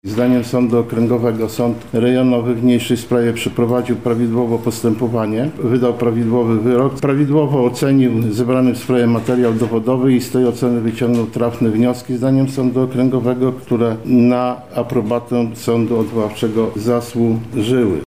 – Postępowanie przed Sądem Rejonowym było przeprowadzone bardzo drobiazgowo – mówił podczas ogłoszenia wyroku sędzia Sądu Okręgowego w Lublinie Wiesław Pastuszak.